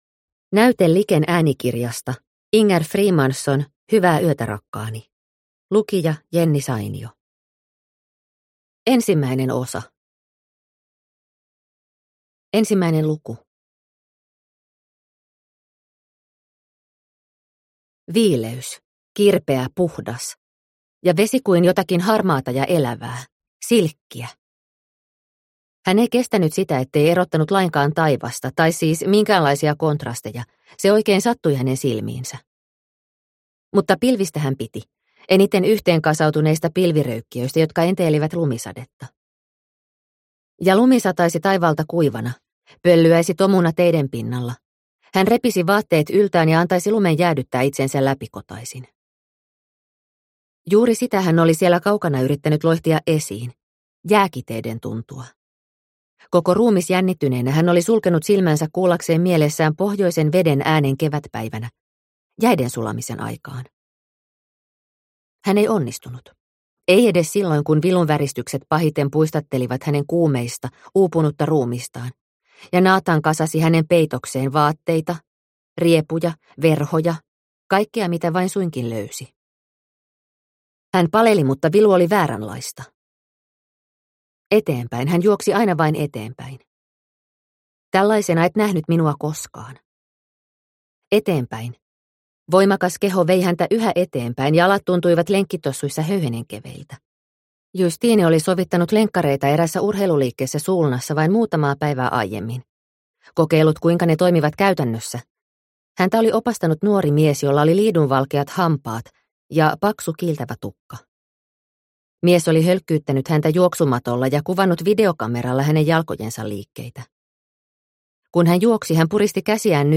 Hyvää yötä, rakkaani – Ljudbok – Laddas ner